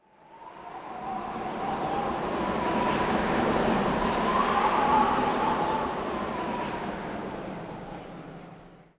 windgust6.wav